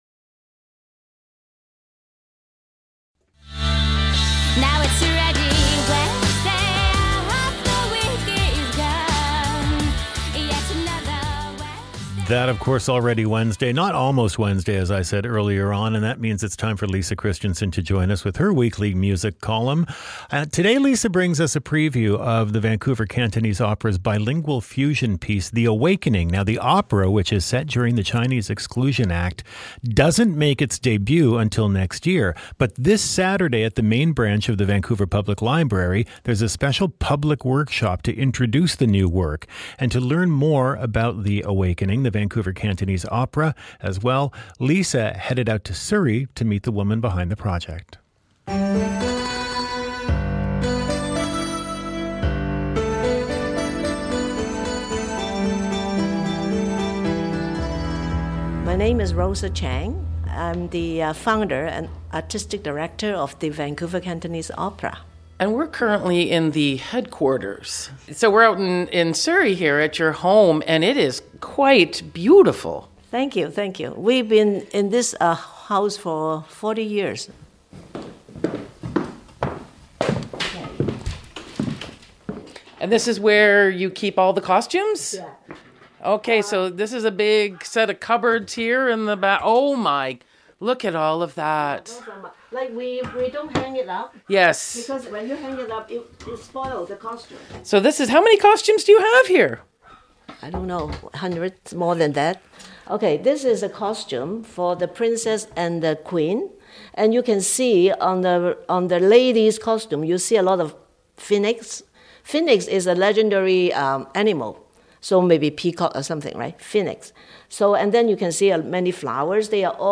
CBC Early Edition Interview